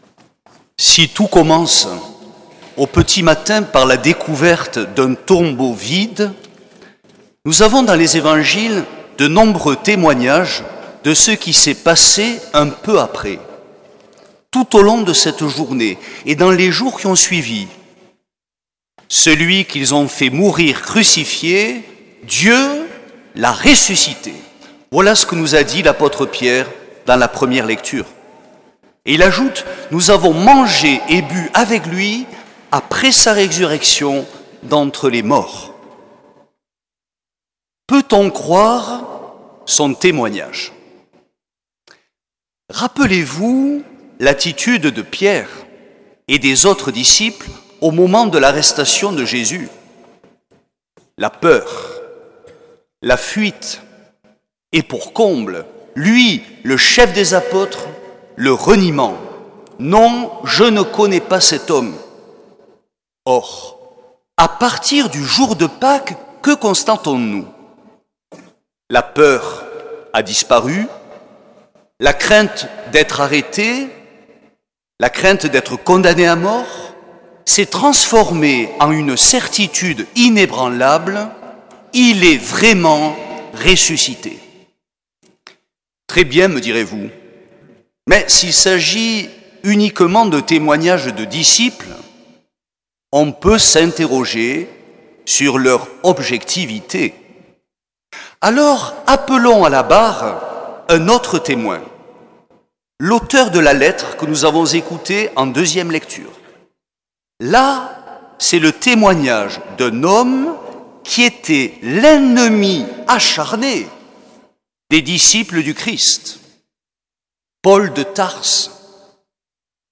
L’enregistrement retransmet l’homélie